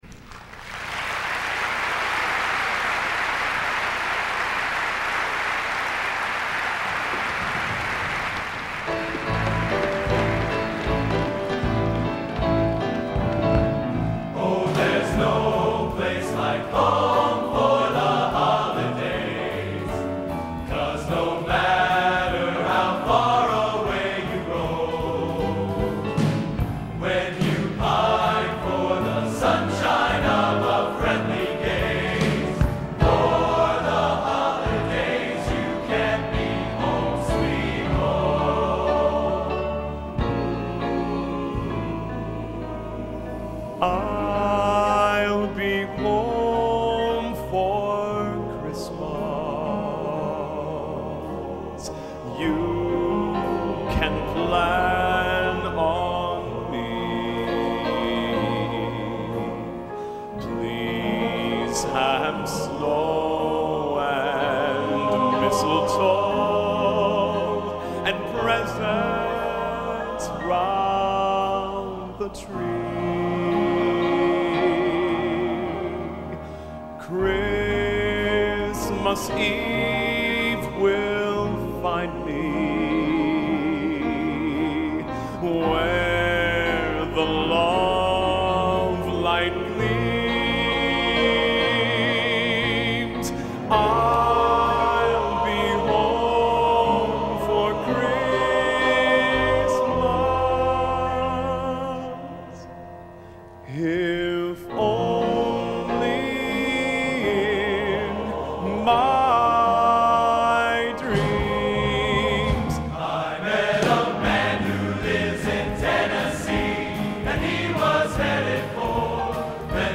Location: West Lafayette, Indiana
Genre: | Type: Christmas Show |